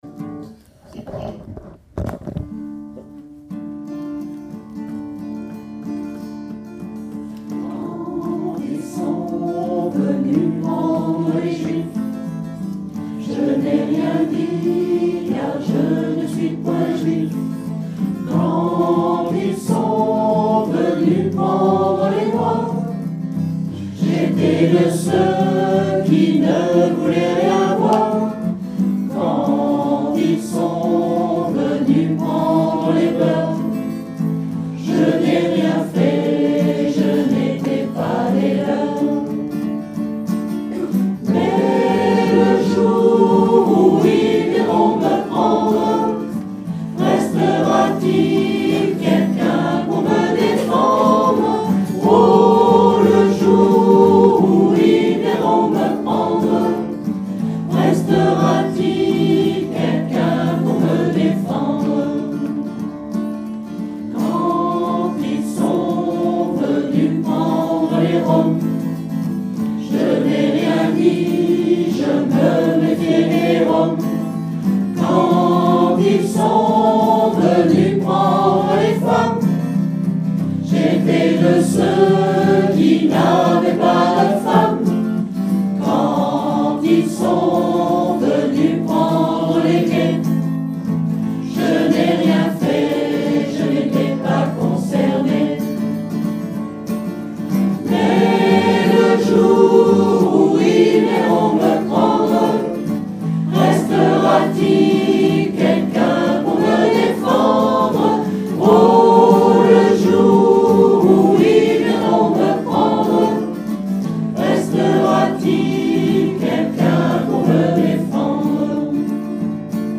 Après avoir essayé la version musicale du groupe Evasion,  nous chantons celle de Marc Robine.
Pour apprendre -  seuls les refrains sont à plusieurs voix